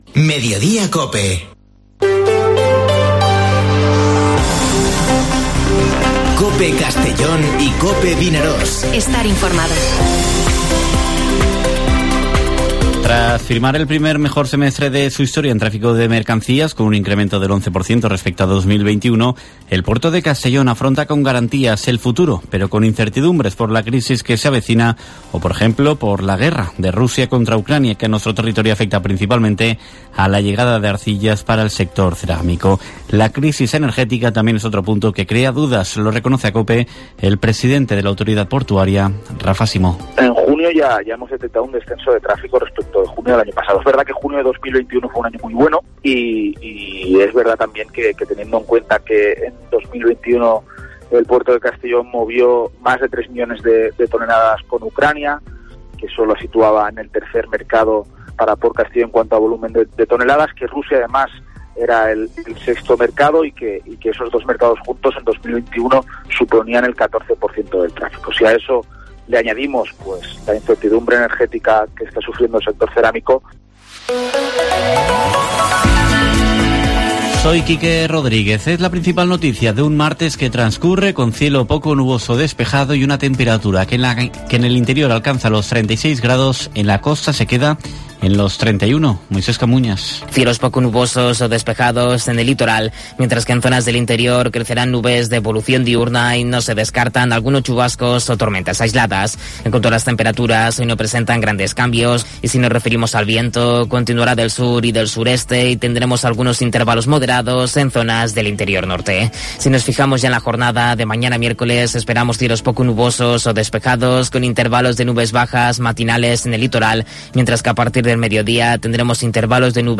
Informativo Mediodía COPE en la provincia de Castellón (19/07/2022)